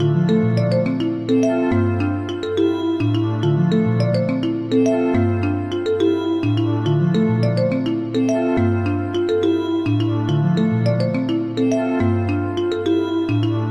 标签： 140 bpm RnB Loops Synth Loops 2.31 MB wav Key : Unknown
声道立体声